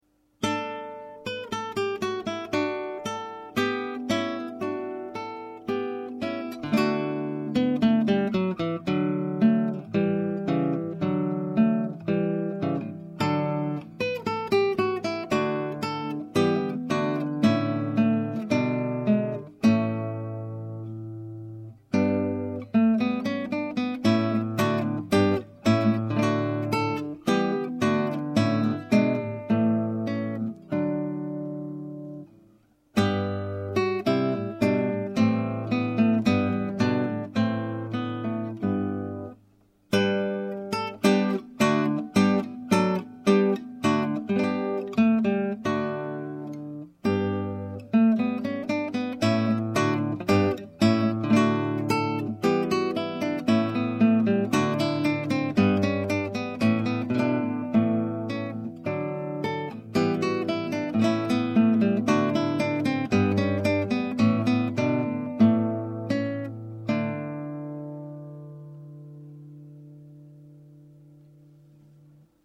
De Pavane is een statige hofdans in een tweedelige maat.